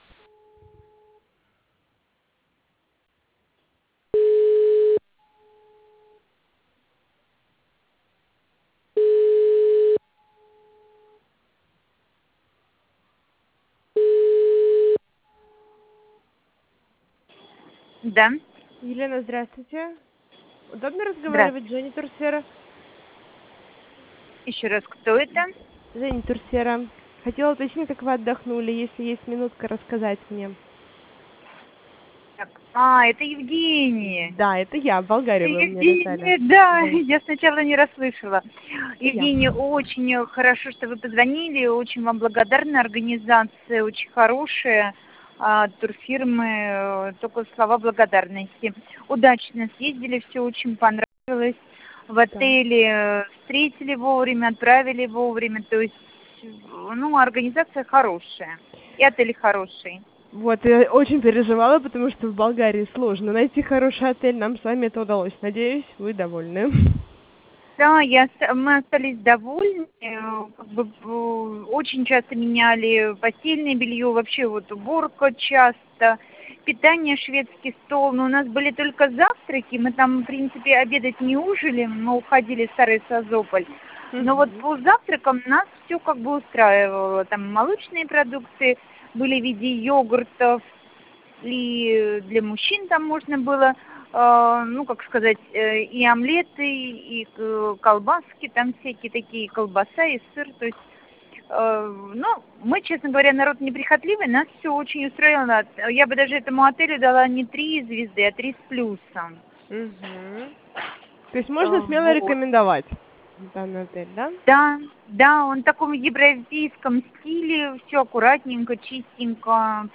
Аудио отзыв об отдыхе в Болгарии в Августе 2017